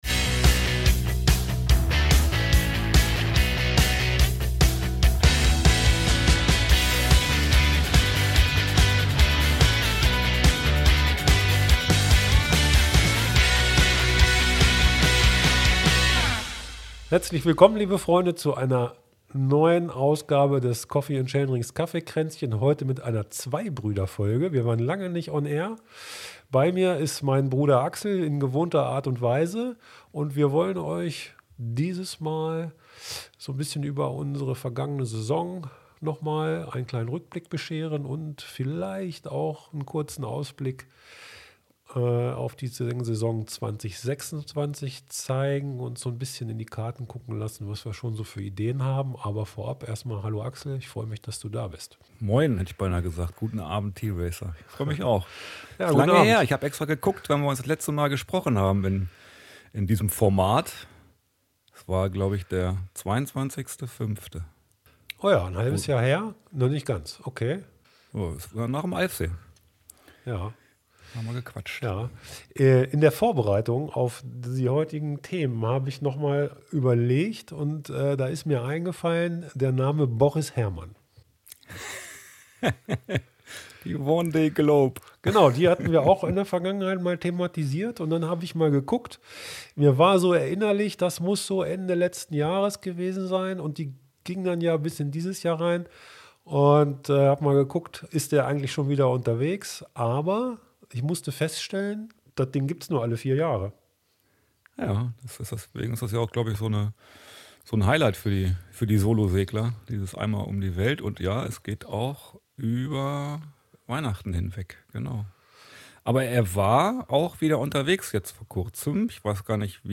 Zwei Brüder, ein Mikrofon – endlich wieder vereint! Nach einer viel zu langen Pause, blicken wir gemeinsam auf die vergangene Saison zurück, sprechen über die aktuelle Form und wagen schon mal einen Blick nach vorne.